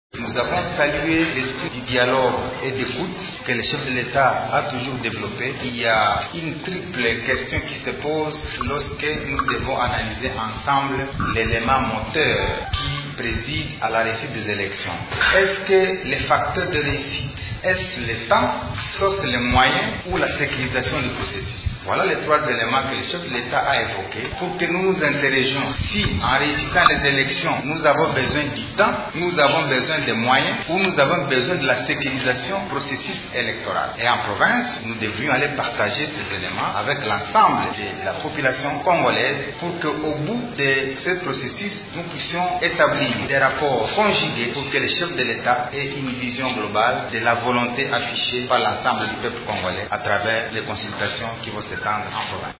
Cliquez ci-dessous pour écoutez le gouverneur Paluku :